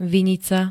Zvukové nahrávky niektorých slov
gqkb-vinica.ogg